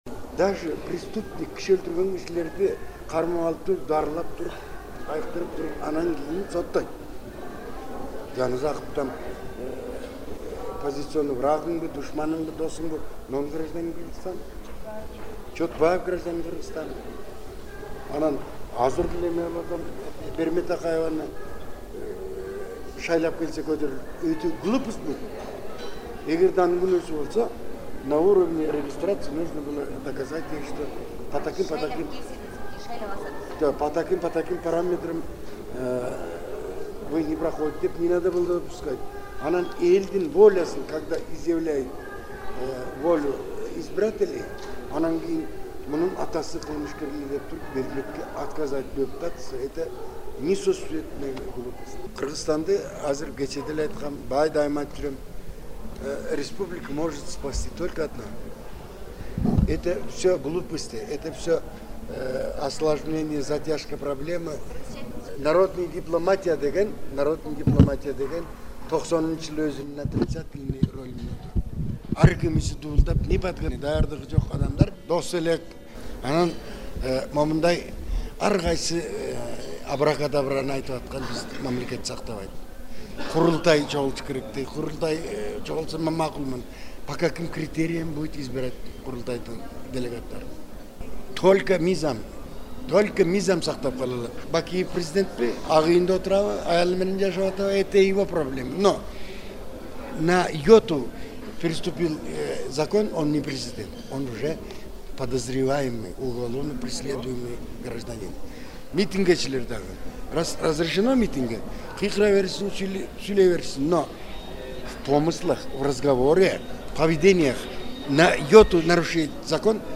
парламенттин имаратында танапис маалында баарлашып отуруп, Кыргызстанды мыйзамдуулук гана сактап каларын мисалдар аркылуу айтып берген.